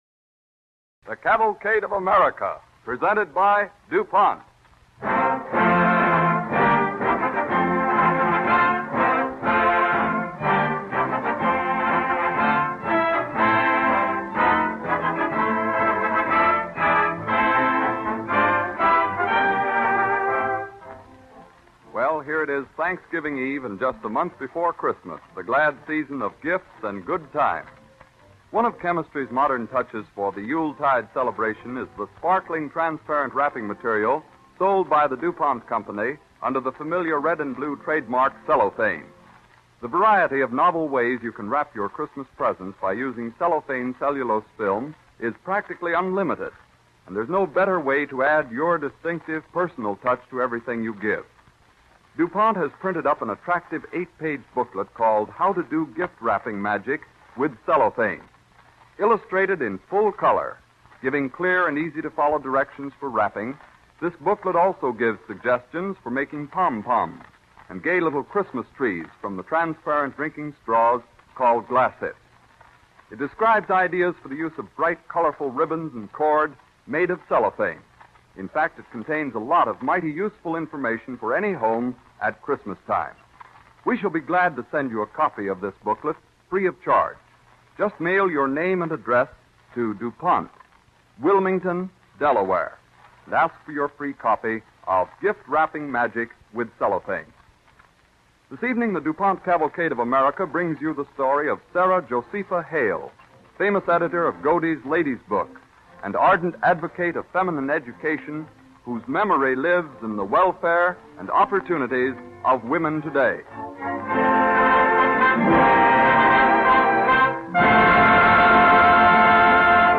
With announcer Dwight Weist